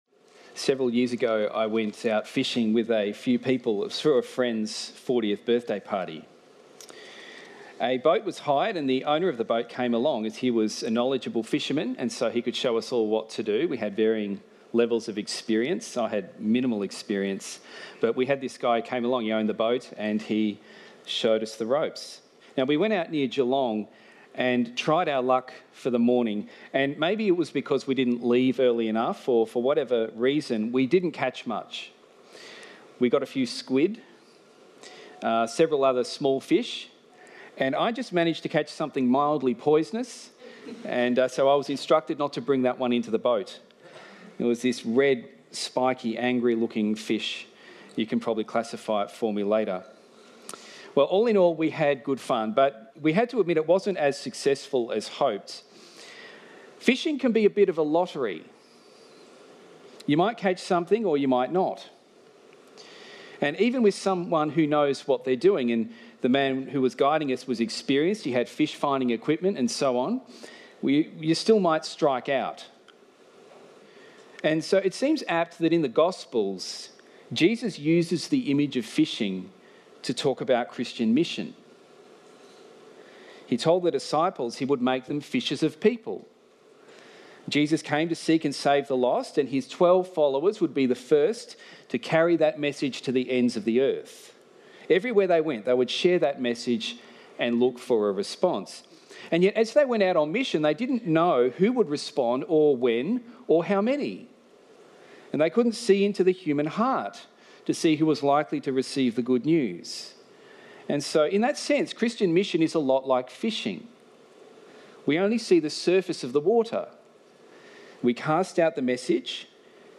The Bible reading is John 21:1-25.